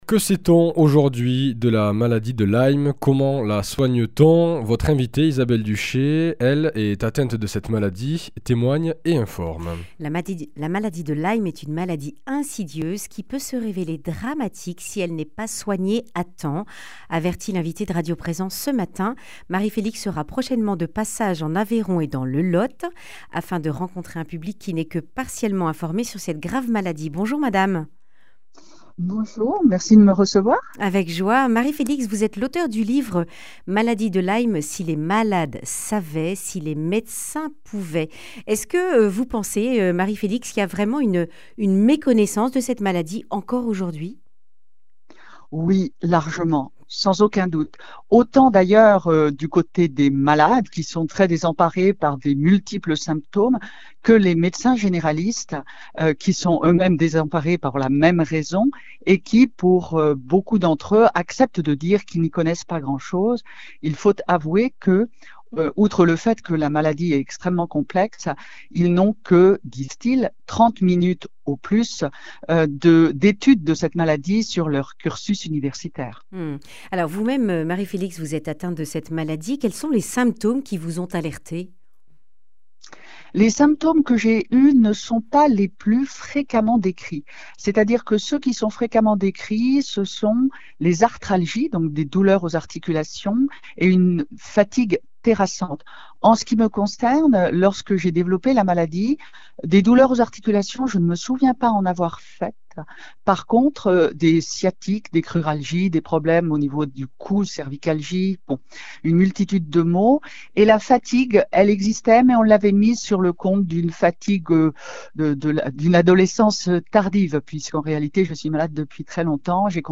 Accueil \ Emissions \ Information \ Régionale \ Le grand entretien \ Maladie de Lyme : où en est-on ?